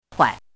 怎么读
huai
huai5.mp3